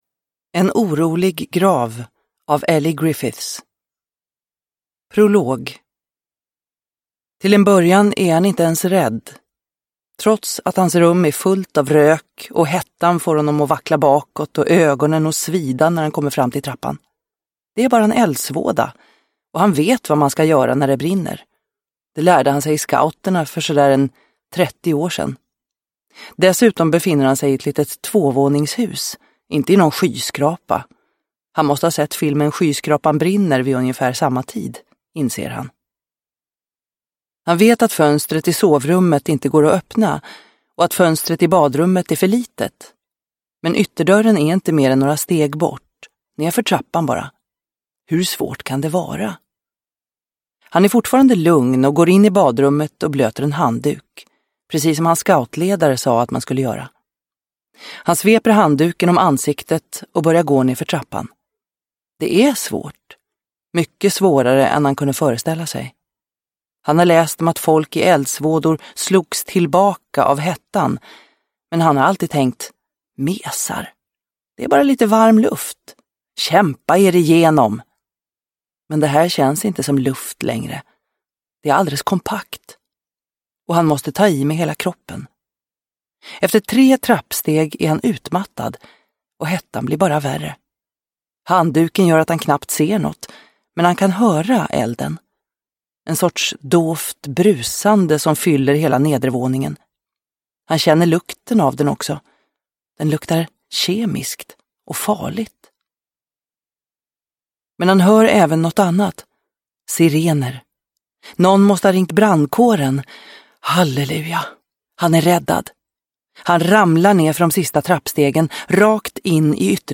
En orolig grav – Ljudbok